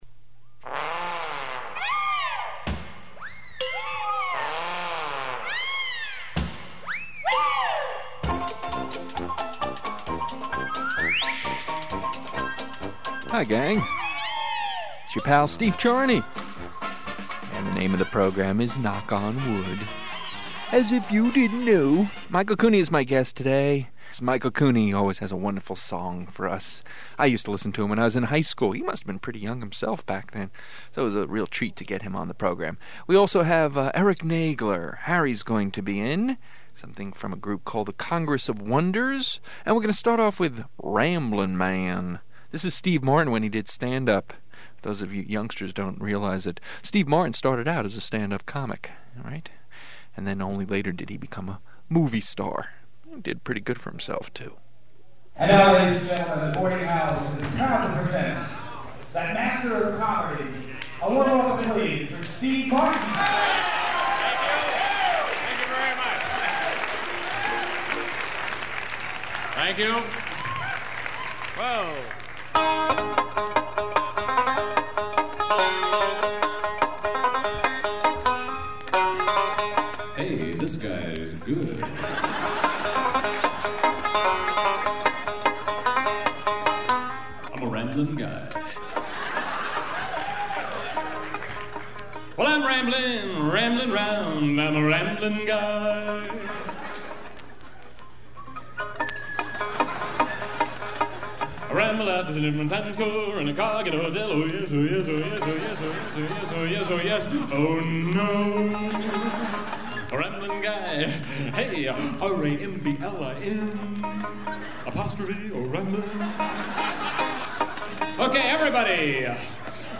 Knock On Wood Comedy Show